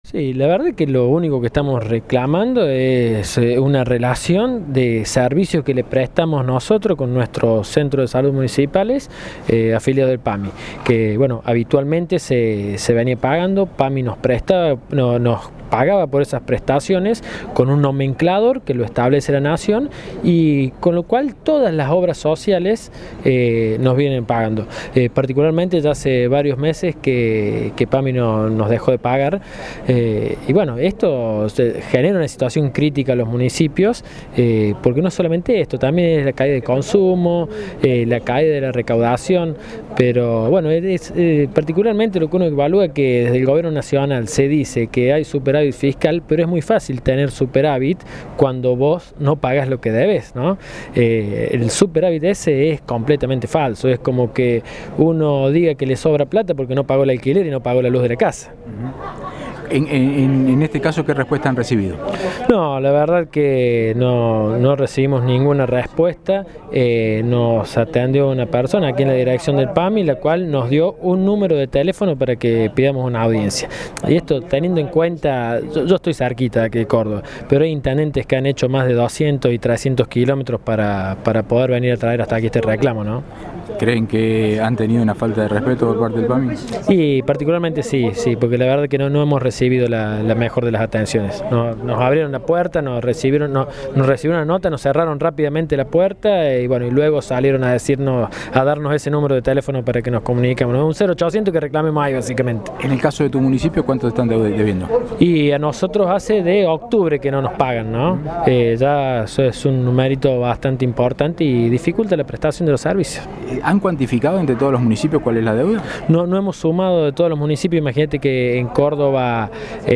Audio: Gastón Mazzalay (Intendente de Malvinas Argentinas).